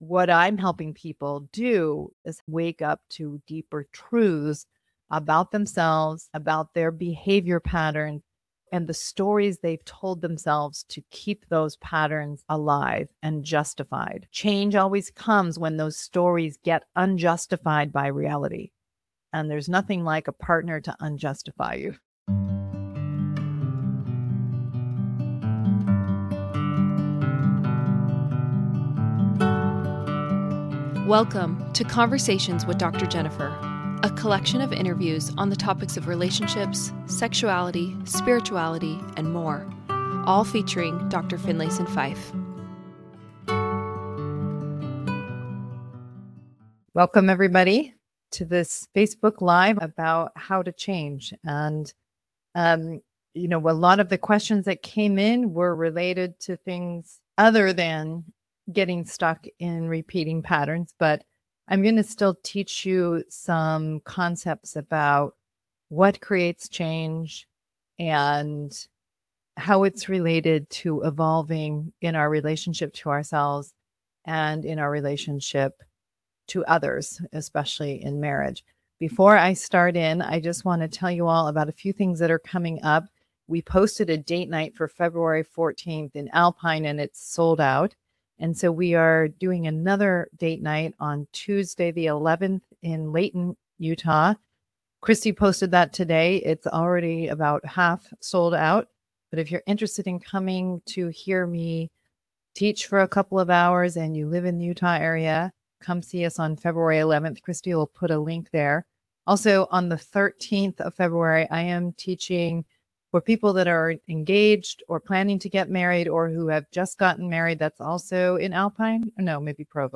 A conversation about creating lasting change in our lives and our relationships.